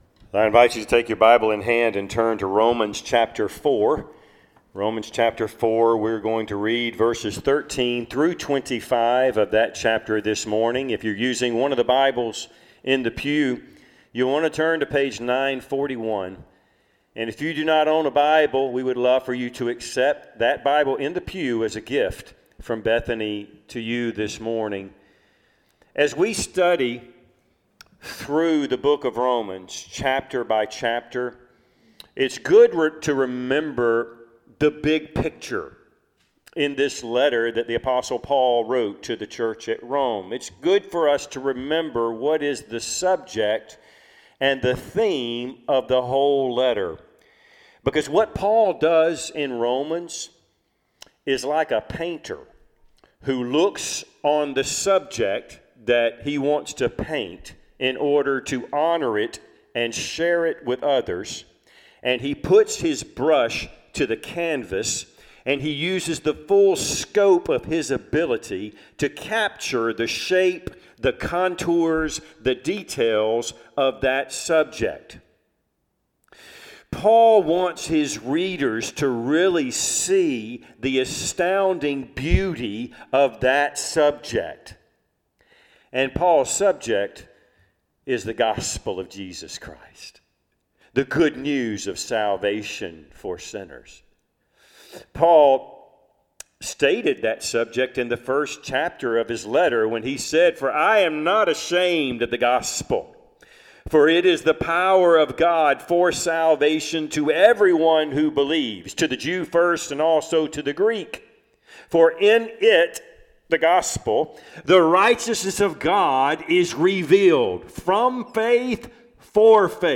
Passage: Romans 4:13-25 Service Type: Sunday AM